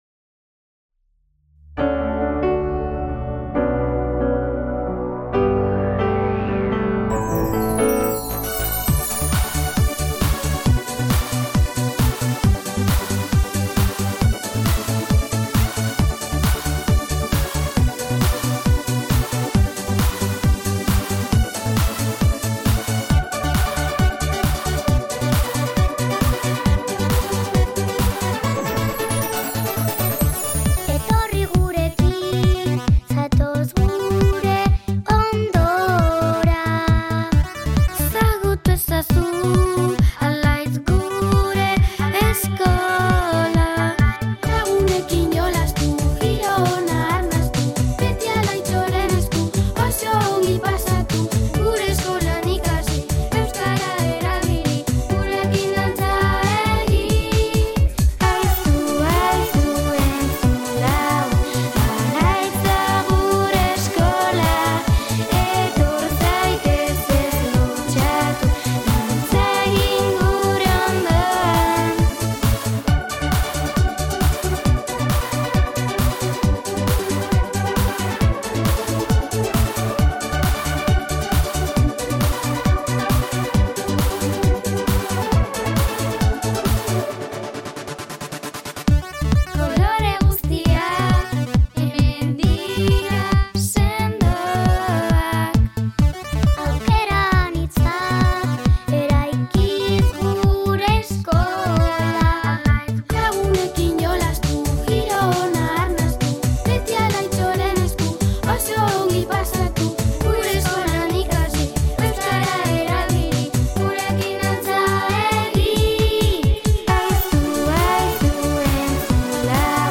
algunas alumnas y alumnos grabaron la nueva canción
en el estudio de grabación